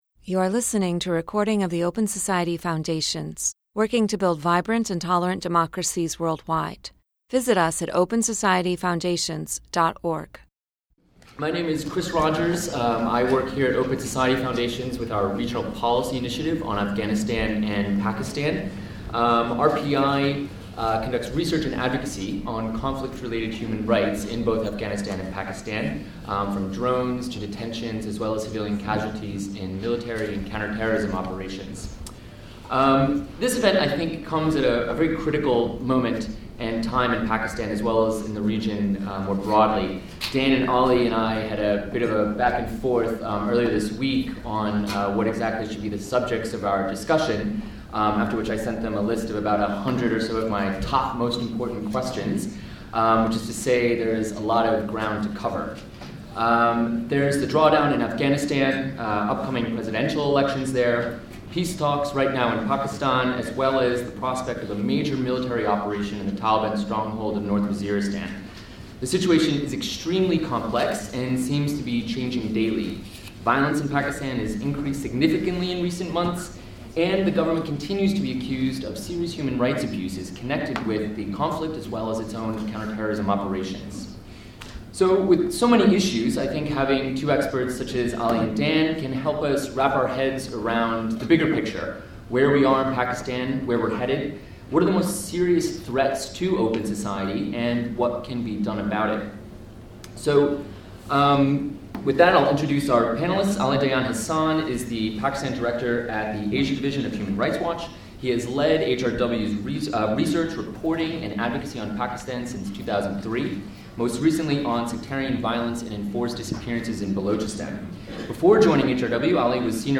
Looking at a pivotal year for Pakistan and the region, experts debate the greatest threats to human rights and security, talks with the Taliban and other militant groups, and what kind of impact the United States, the international community, and civil society can have on security and human rights.